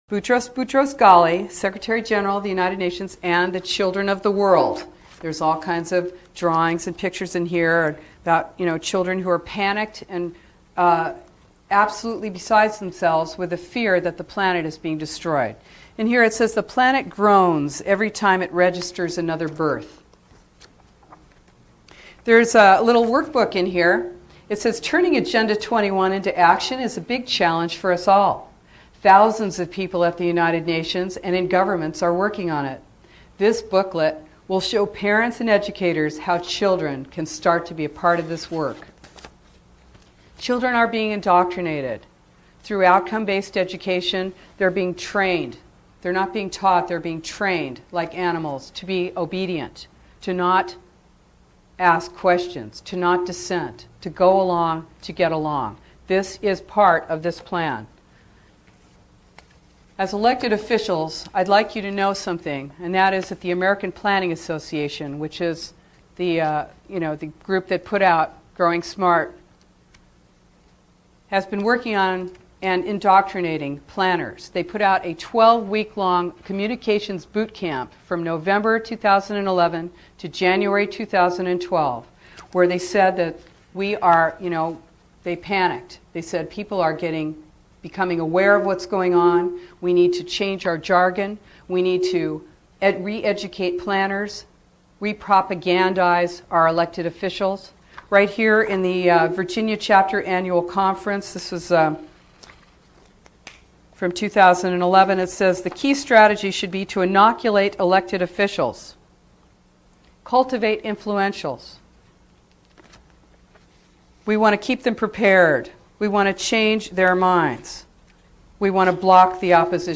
It took place in the Legislative Office Building just behind the Capital in Concord on June 25, 2012. She shared with concerned legislators what she has learned about the true nature of Sustainable Development.